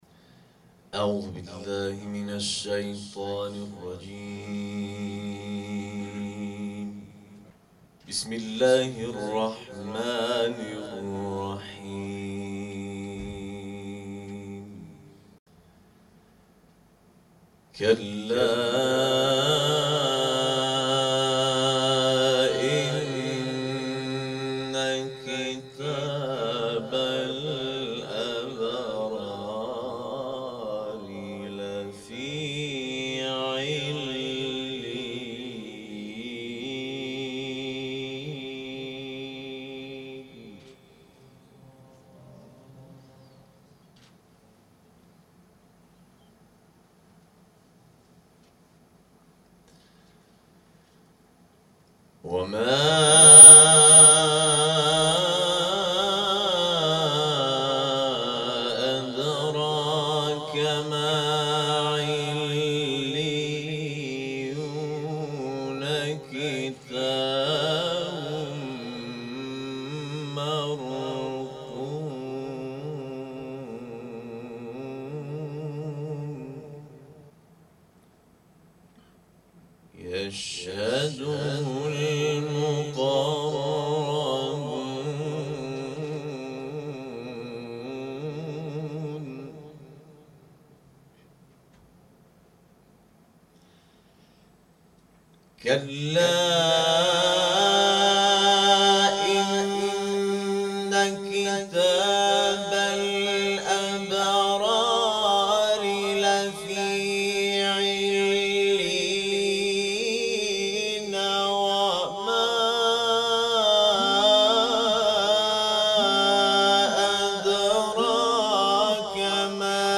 در محفل انس با قرآن شرکت و آیاتی از کلام‌ الله مجید را تلاوت کرد
صوت تلاوت آیات 18 تا 28 سوره مطففین